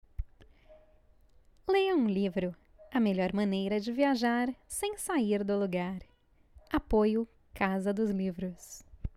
Sweet voice, good for sales, serious.
Sprechprobe: Sonstiges (Muttersprache):